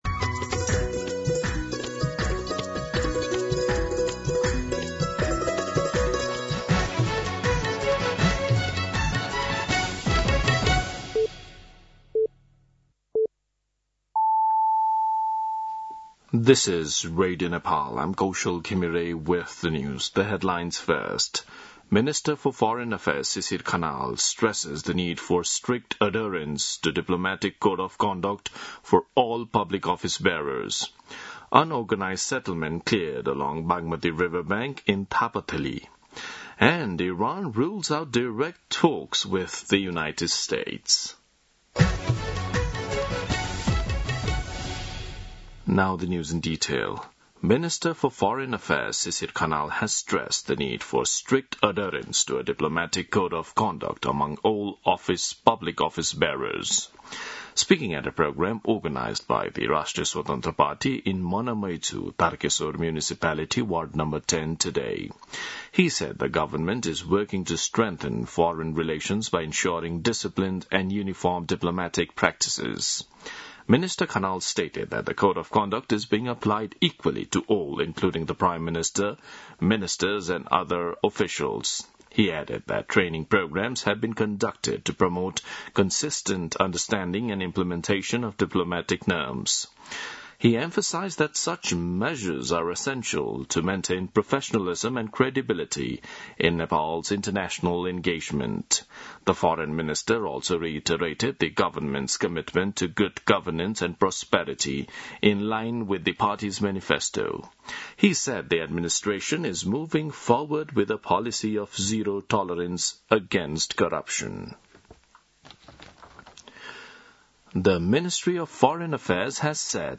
दिउँसो २ बजेको अङ्ग्रेजी समाचार : १२ वैशाख , २०८३
2pm-English-News-1-12.mp3